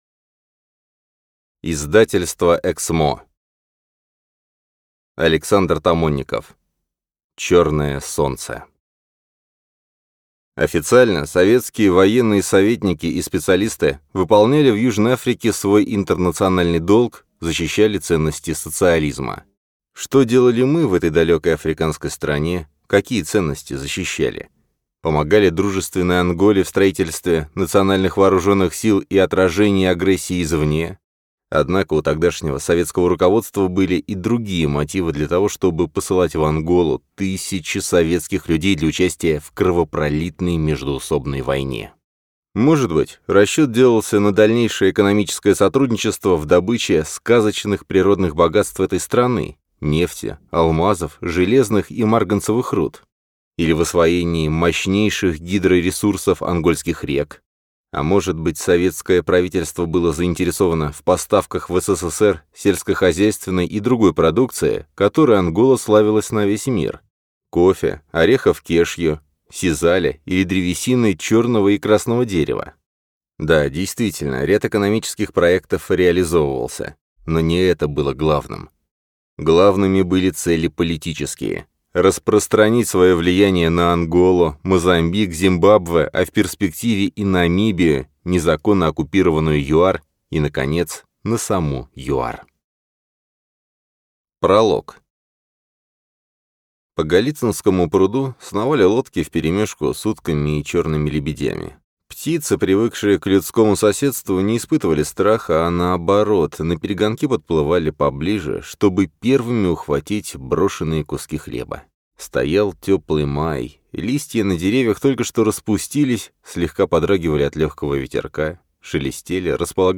Аудиокнига Черное солнце | Библиотека аудиокниг
Прослушать и бесплатно скачать фрагмент аудиокниги